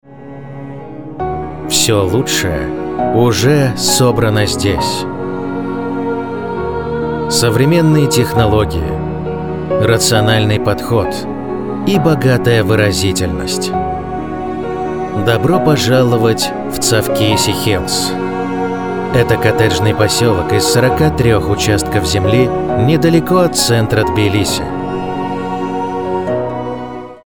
Муж, Рекламный ролик/Зрелый
Дикторская кабина, Lewitt LCT440 PURE, Audient iD4 MKII.